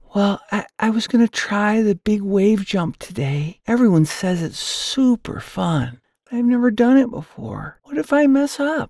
Child Voice Demos
Young Dolphin Character
Words that describe my voice are articulate, sincere, narrator.
0109Childrens_Audiobook__Donny_Dolphin.mp3